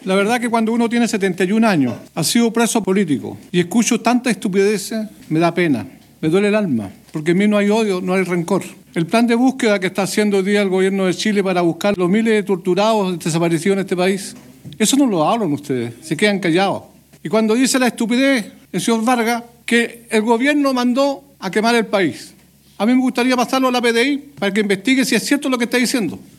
El socialista Manuel Rivera, detalló que “cuando escucho tantas estupideces, me da pena“.